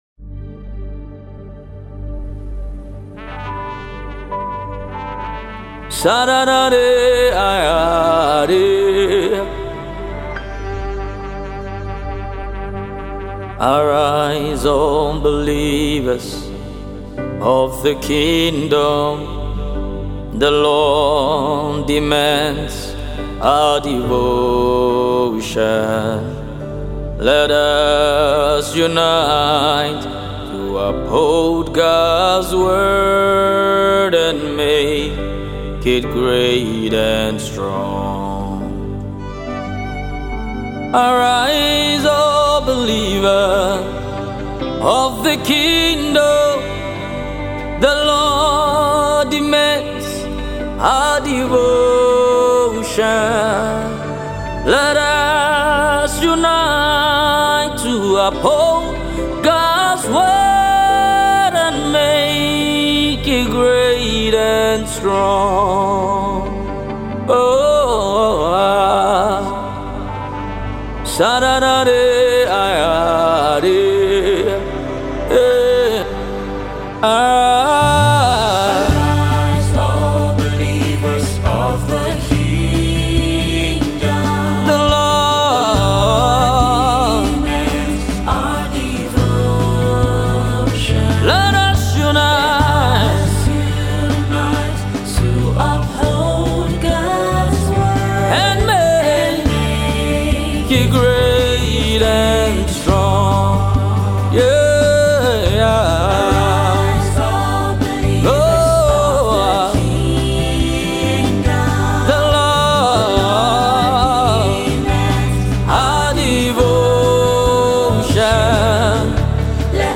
He is out with another soul arousing single